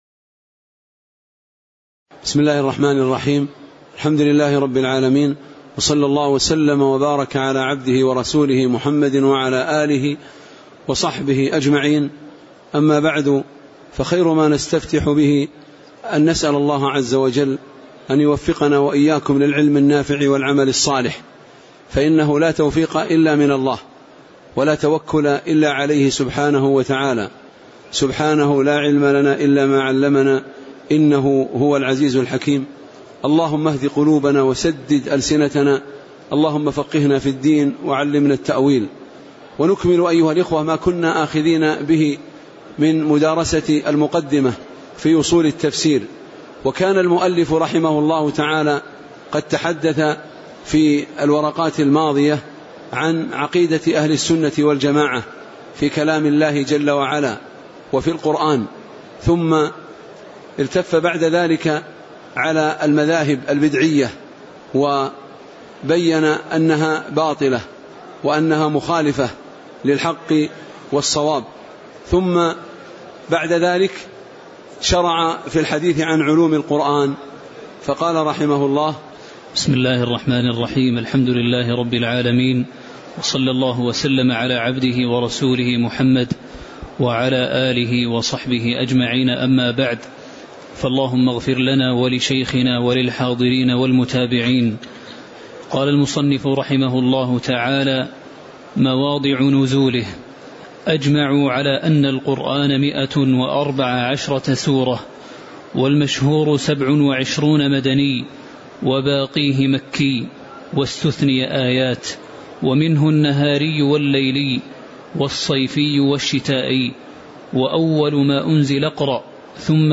تاريخ النشر ١١ شوال ١٤٣٩ هـ المكان: المسجد النبوي الشيخ